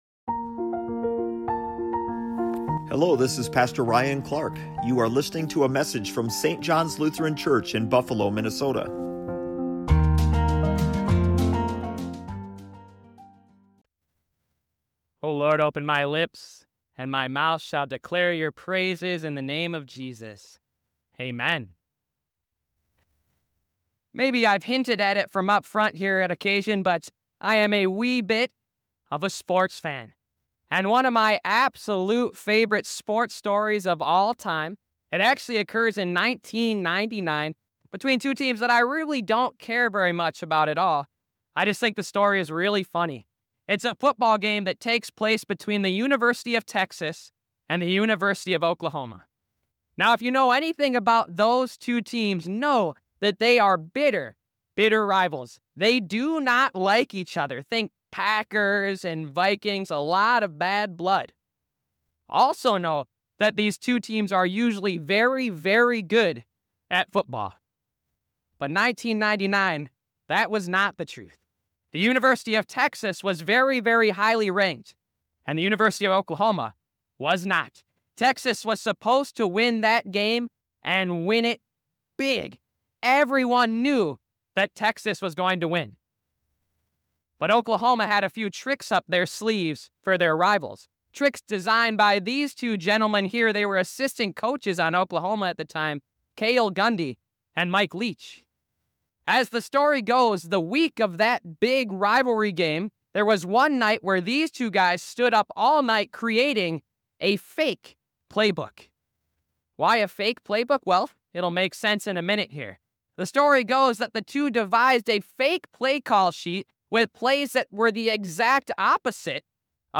Today we're continuing our sermon series titled Made for CommUNITY God's Plan for Us. And in this sermon series we're talking about what God pleasing interactions with one another look like, based on the one another passages that we can find throughout the Bible.